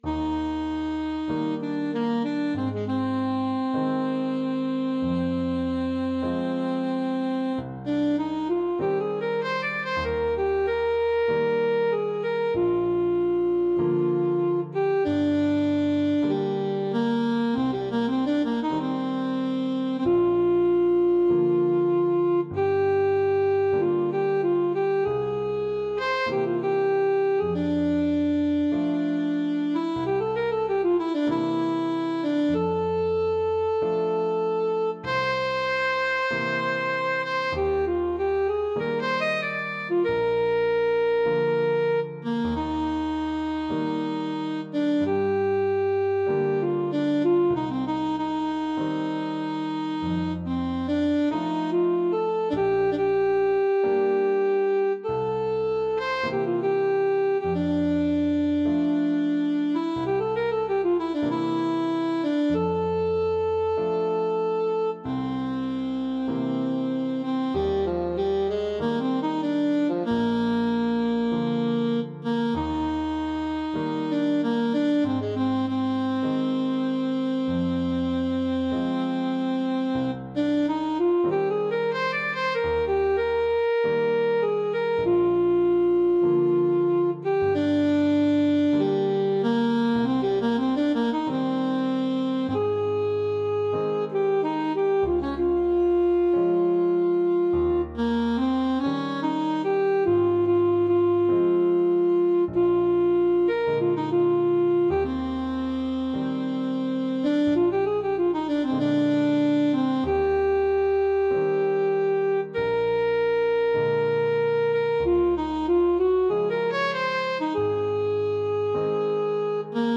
Classical Satie, Erik Gnossienne 5 Alto Saxophone version
Eb major (Sounding Pitch) C major (Alto Saxophone in Eb) (View more Eb major Music for Saxophone )
Modere ( = 48)
Classical (View more Classical Saxophone Music)